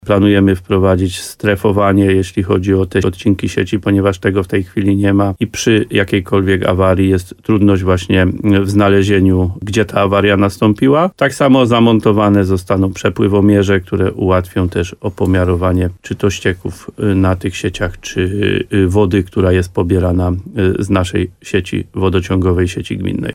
Jak powiedział wójt gminy Łososina Dolna Adam Wolak, konkretnym efektem prac będzie możliwość szybkiego niwelowania awarii.